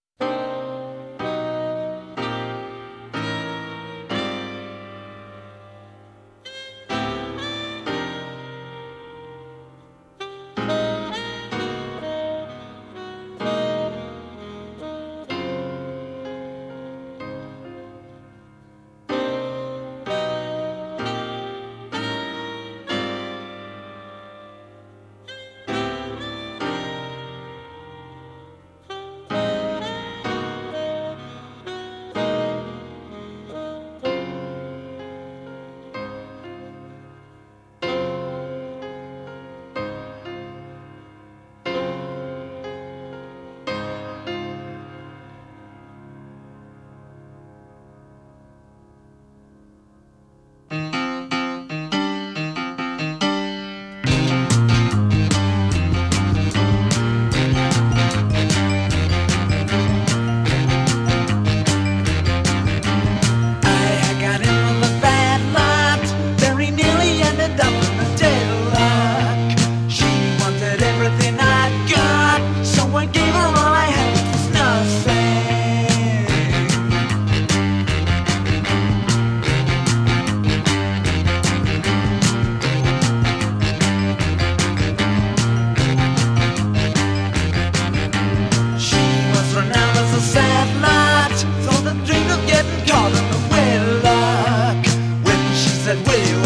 Progressive era